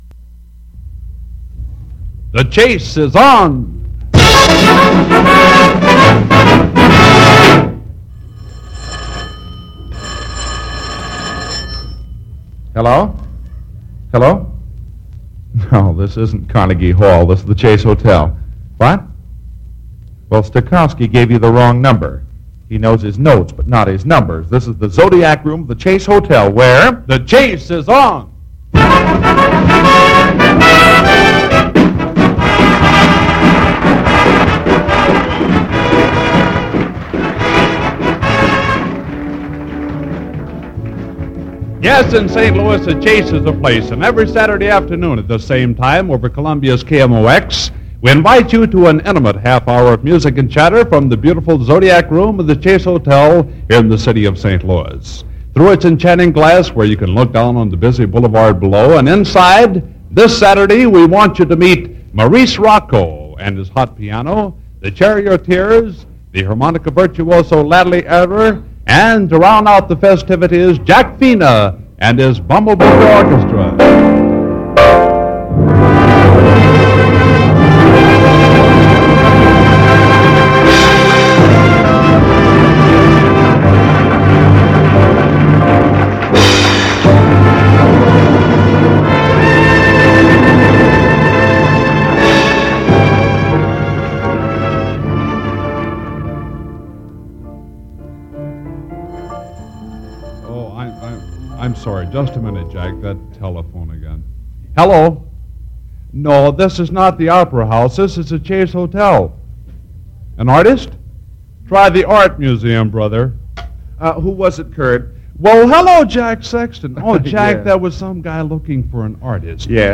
Saturday At The Chase aircheck